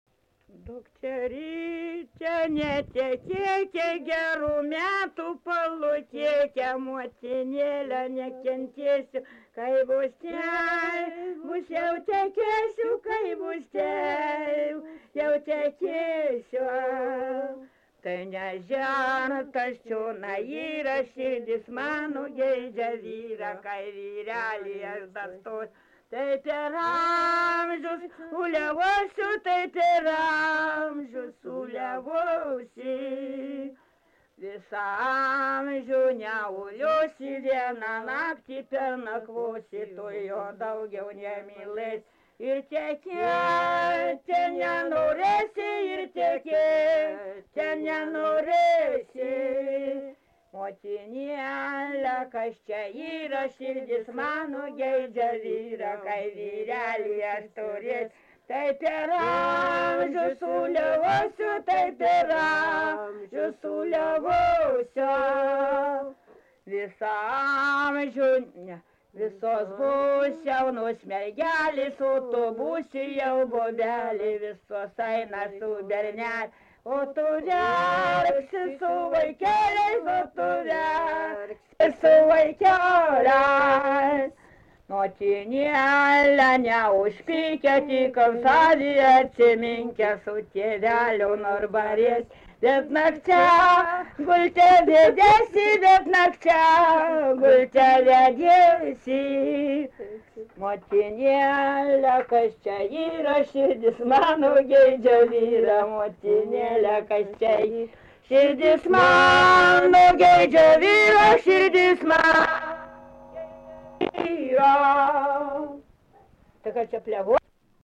Subject daina
Erdvinė aprėptis Kvietkinė
Atlikimo pubūdis vokalinis